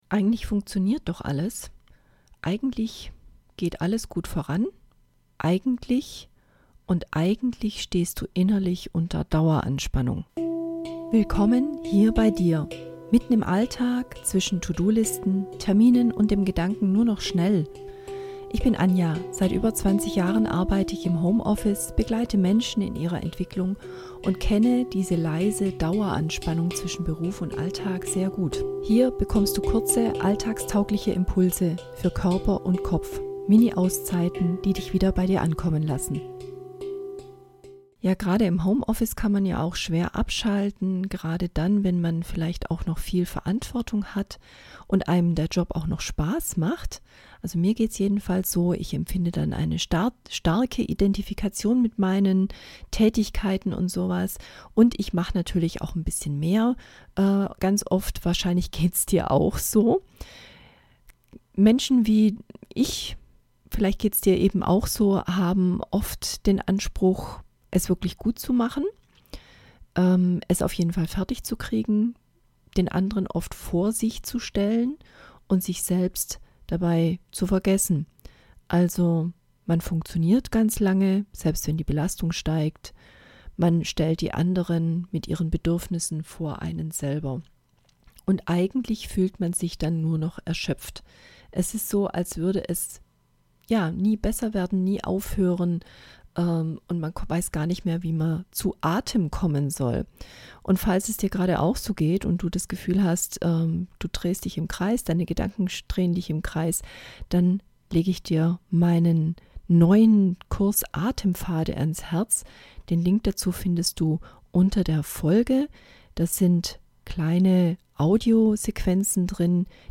geführte Impulse für deine Pause